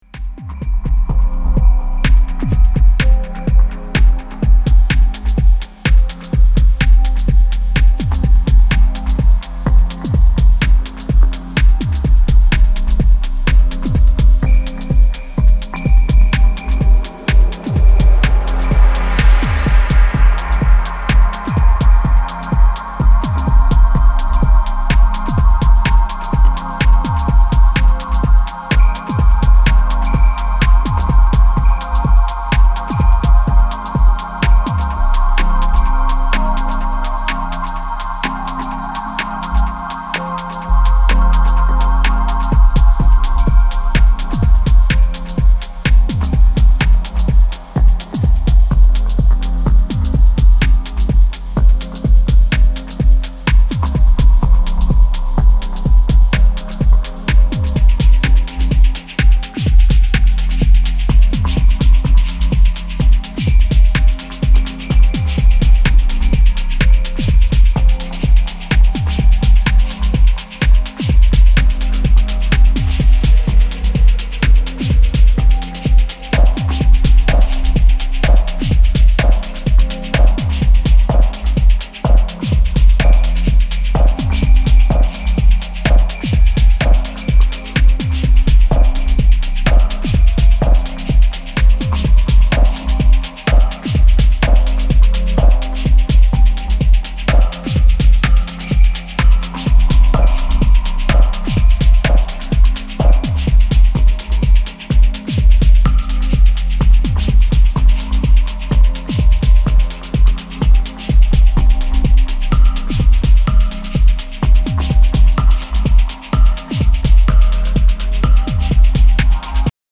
重々しく流動する低音の壁に固く打ち付けられるキックとハットが非常にファンキーな
ダブ・テクノの新しい潮流を感じさせる一枚です。